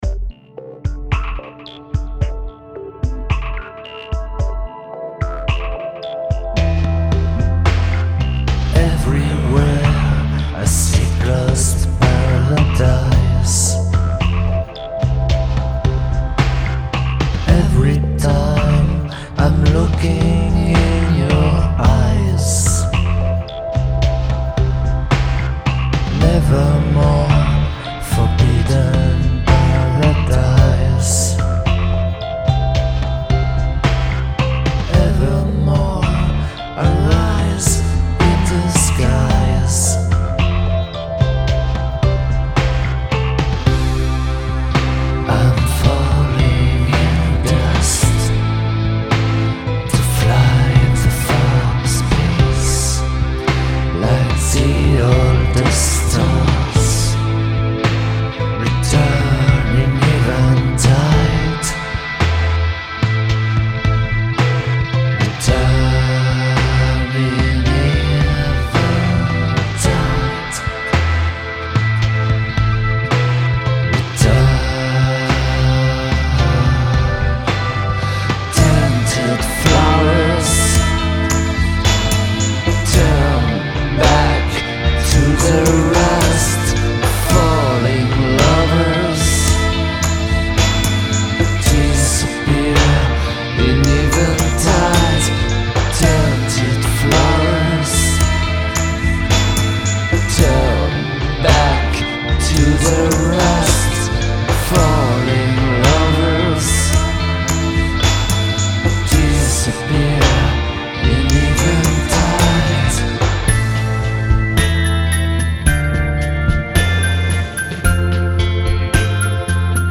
guitare, clavier, basse, programmation
voix, clavier, programmation, mixage